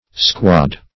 Squad \Squad\ (skw[o^]d), n. [F. escouade, fr. Sp. escuadra, or